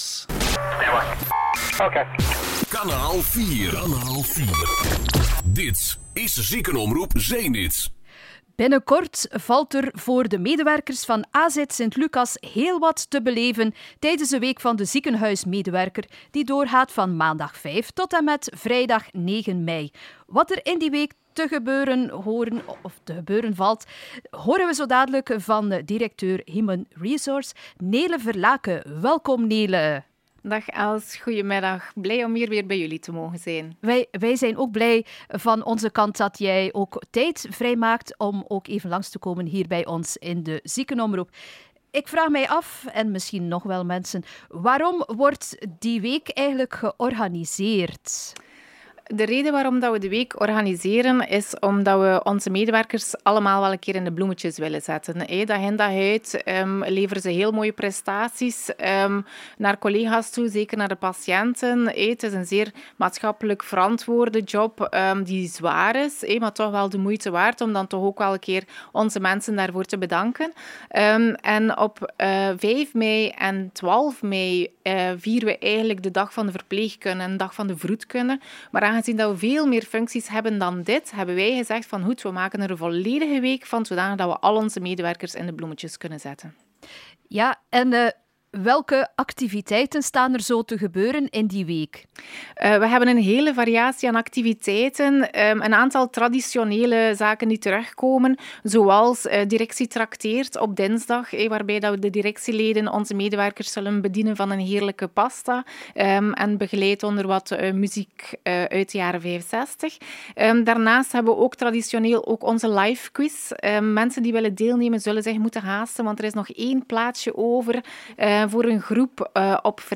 Interviews
Week van de ziekenhuismedewerker - Interview